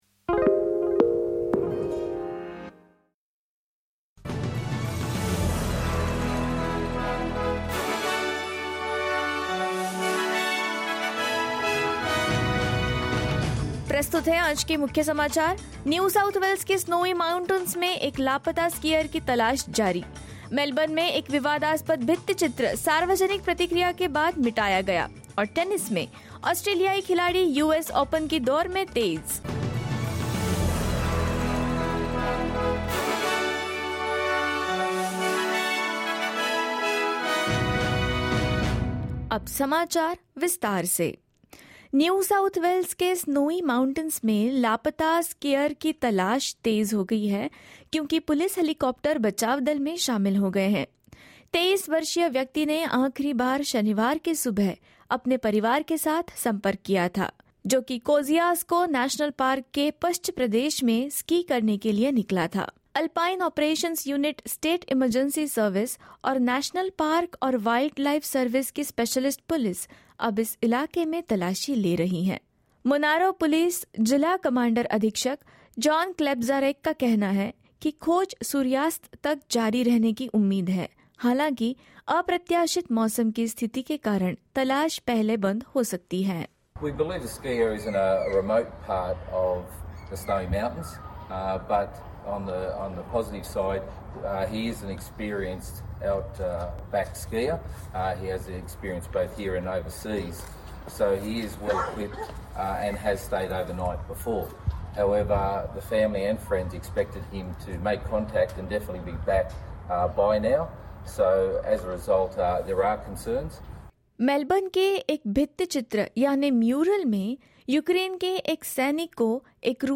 SBS Hindi News 5 September 2022: Controversial mural in Melbourne faces public backlash